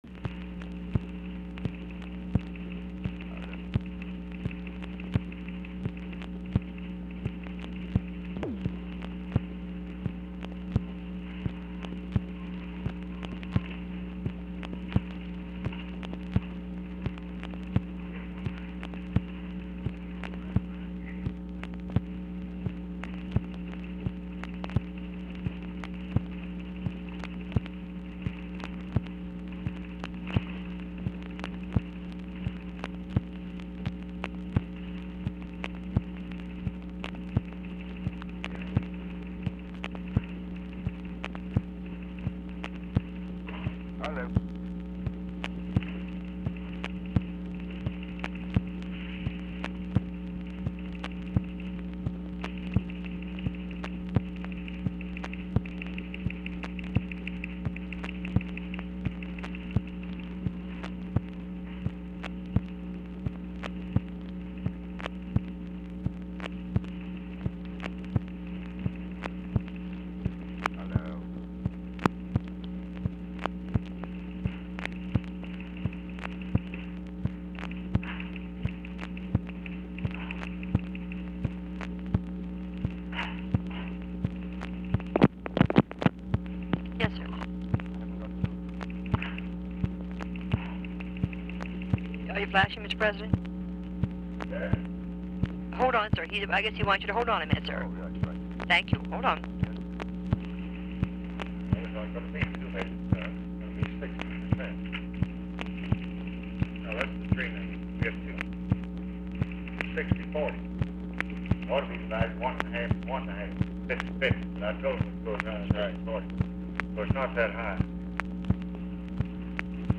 OFFICE CONVERSATION AND NOISE PRECEDES CALL
NEHRU DIFFICULT TO HEAR
Format Dictation belt
Location Of Speaker 1 Oval Office or unknown location
Other Speaker(s) OFFICE NOISE, OFFICE CONVERSATION
Specific Item Type Telephone conversation